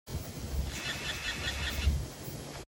M1 iMac buzzing whining noise with low display brightness
This is what the noise sounds like when doing an 'intensive' task - although this was just visiting a certain webpage which makes it chirp loudly for some reason. Forgive the background noise (I tried to remove some of it). I had the mic right up to the lower chin of the Mac so bare in mind it's considerably louder in my recording than when you're sat at a normal distance, albeit still very noticeable ?